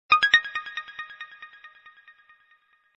Стандартное SMS на Sony Ericsson XPeria